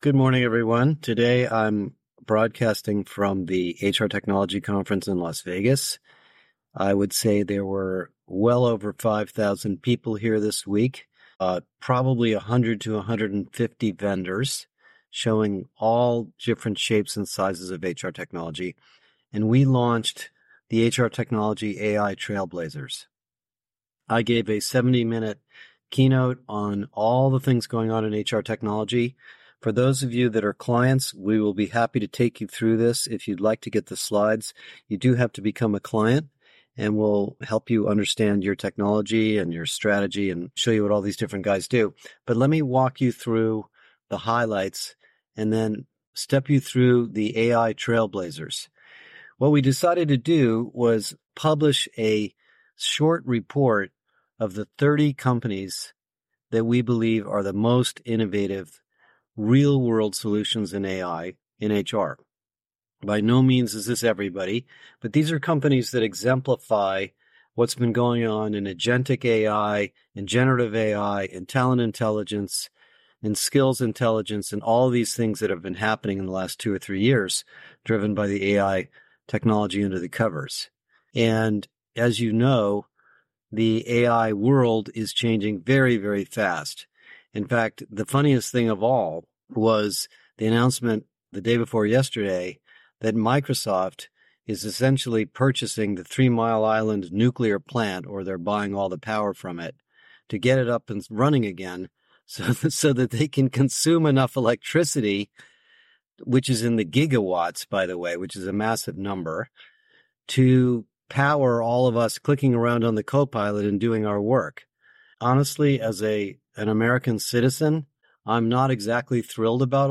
Live from HR Technology 2024: A Detailed Look At The AI Trailblazers! E186